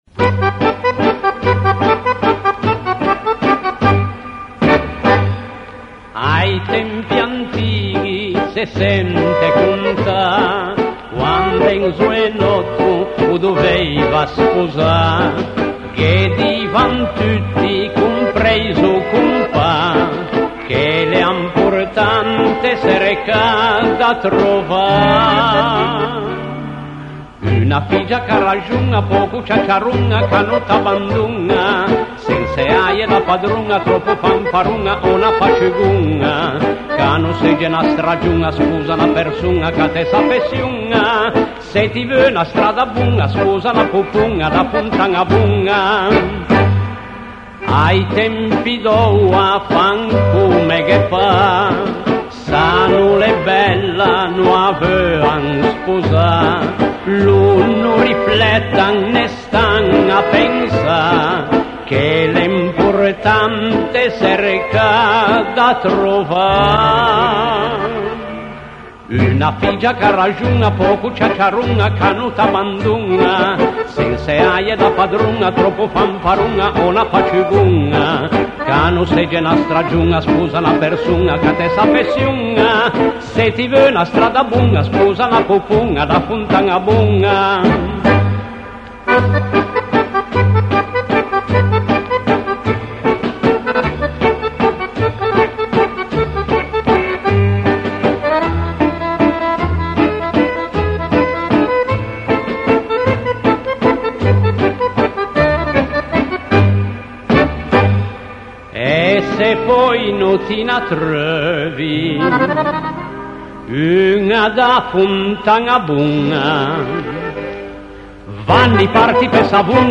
[ Cansoìn zenéixi ]